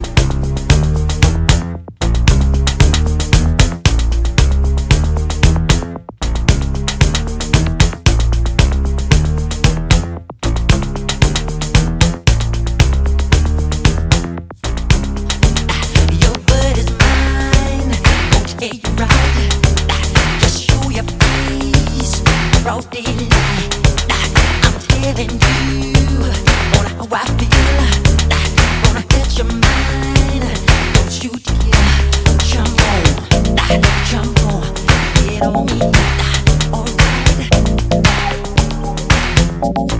танцевальные
фанк
dance pop